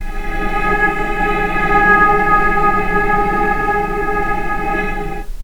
vc_sp-G#4-pp.AIF